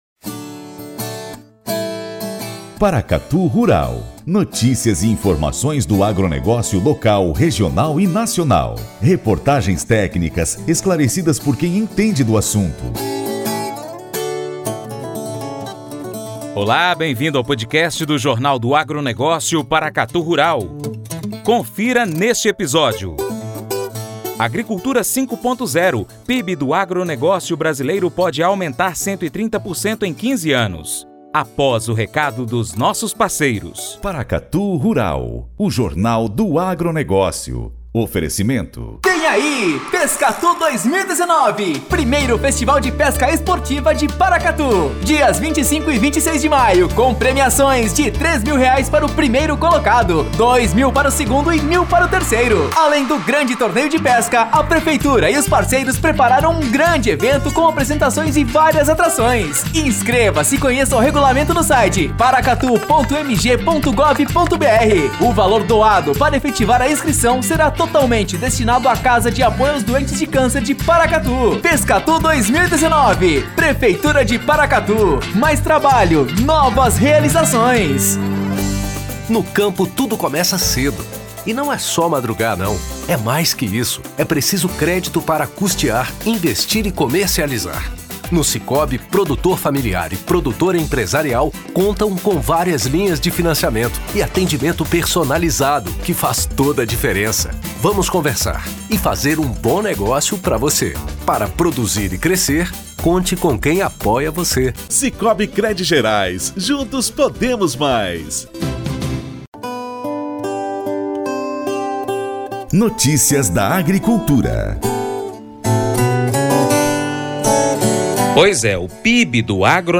tiveram um Dedo de Prosa com Fernando Camargo, Secretário de Inovação, Desenvolvimento Rural e Irrigação do MAPA.